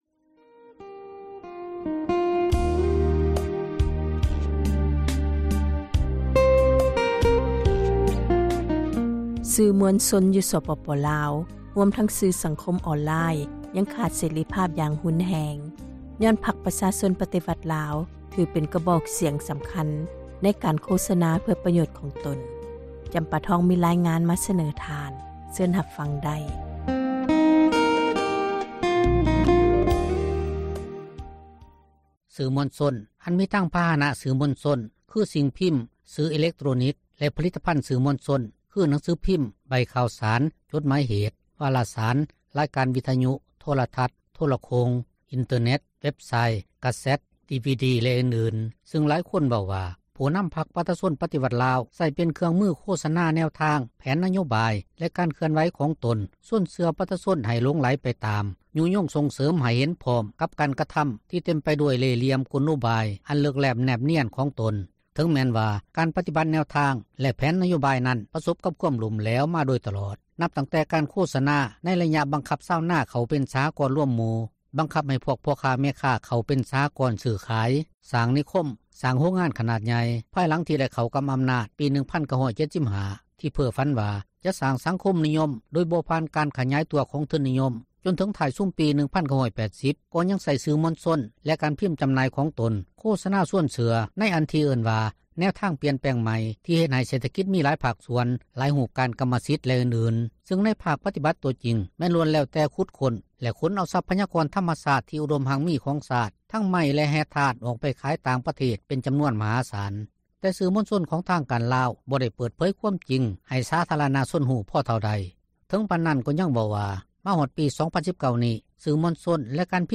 ໃຫ້ສຳພາດວິທຍຸເອເຊັຍເສຣີ